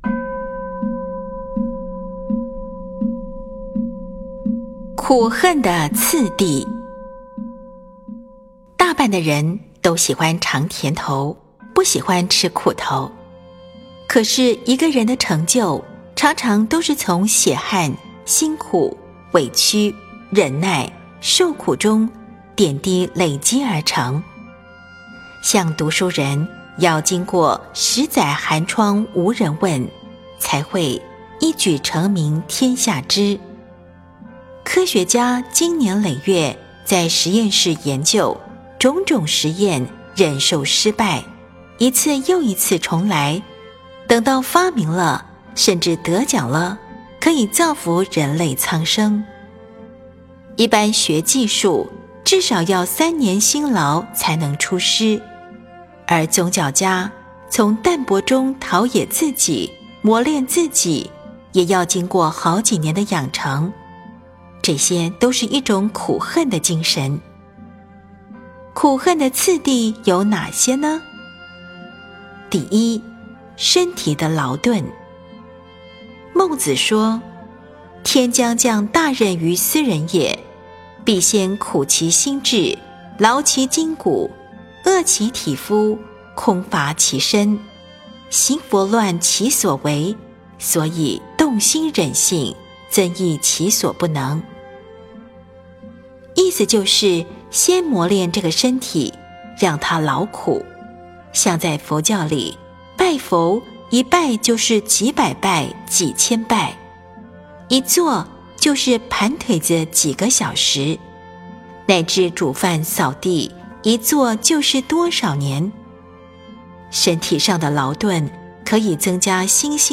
94.苦行的次第 诵经 94.苦行的次第--佚名 点我： 标签: 佛音 诵经 佛教音乐 返回列表 上一篇： 金刚经：第九品和第十品 下一篇： 金刚经：第二十三品和第二十四品 相关文章 楞伽经（偈颂品第十之二） 楞伽经（偈颂品第十之二）--未知...